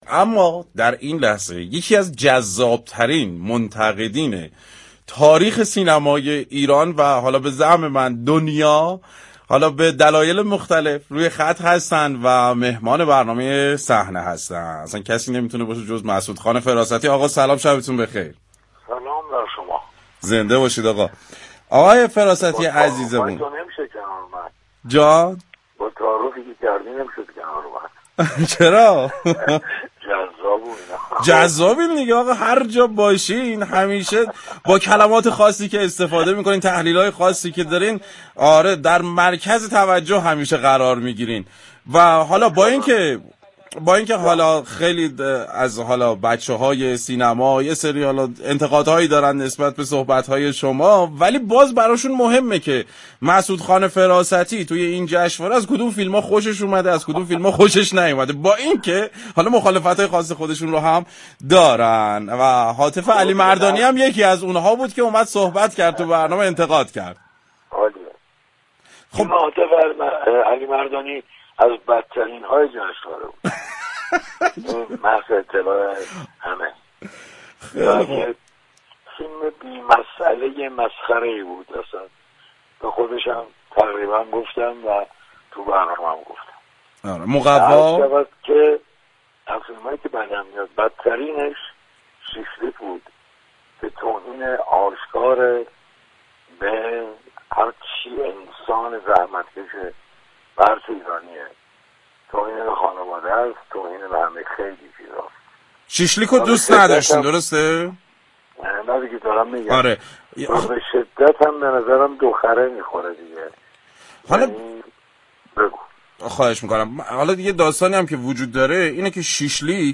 مسعود فراستی مهمان تلفنی برنامه صحنه